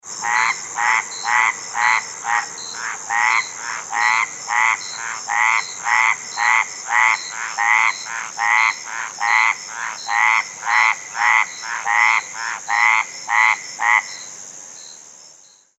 Lowland Burrowing Treefrog - Smilisca fodiens
Advertisement Calls
The advertisement call of the Lowland Burrowing Treefrog is a loud, low-pitched, metallic honk or quack, with no rising inflection, repeated two to three times per second.
Sound  This is a 15 second recording of the advertisement calls of two male frogs calling at night in Pima County, Arizona, (seen to the right) with insect sounds in the background.